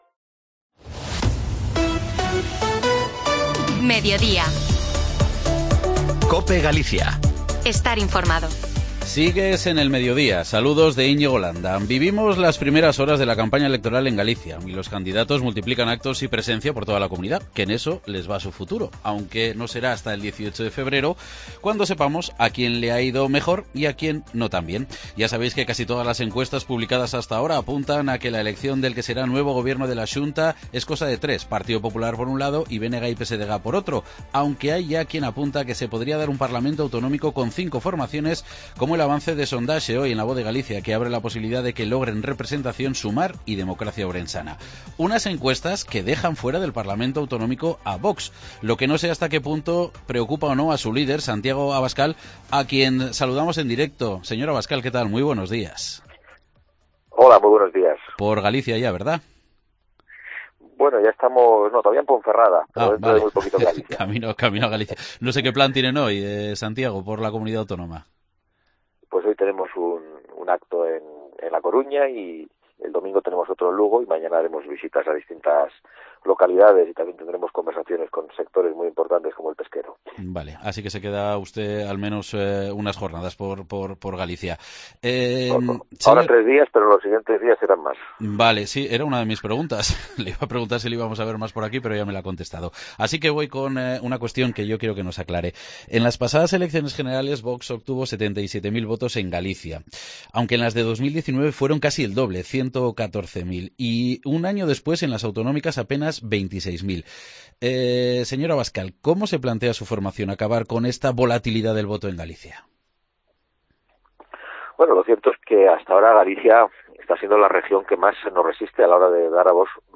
AUDIO: En el arranque de la campaña electoral en Galicia, entrevistamos al líder nacional de VOX, Santiago Abascal